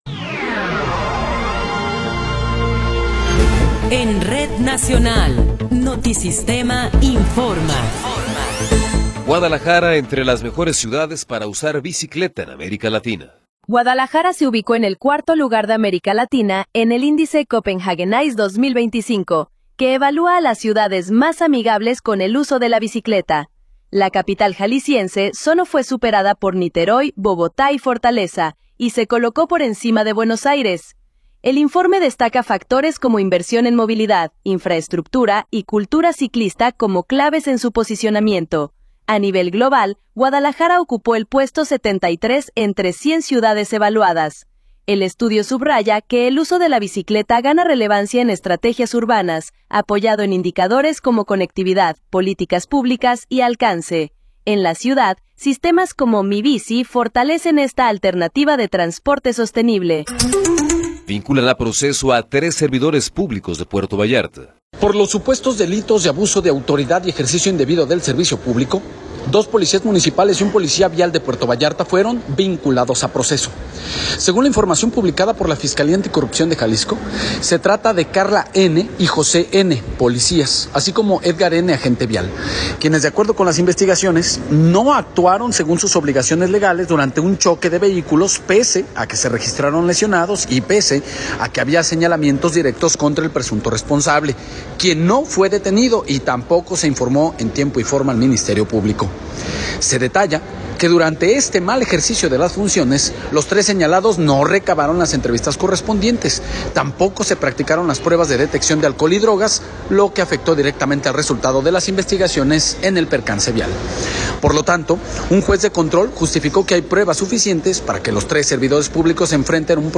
Noticiero 18 hrs. – 25 de Abril de 2026
Resumen informativo Notisistema, la mejor y más completa información cada hora en la hora.